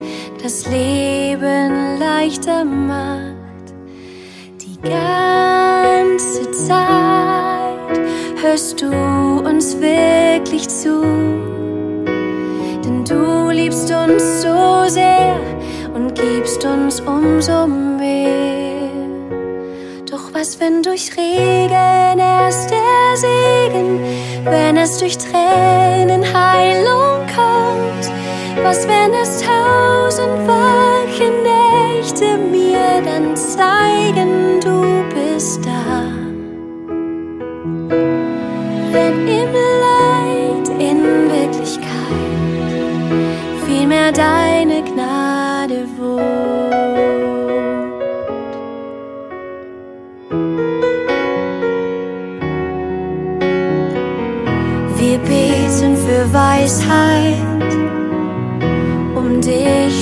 Text) Worship 0,99 €